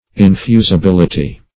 Infusibility \In*fu`si*bil"i*ty\, n. [From Infuse.]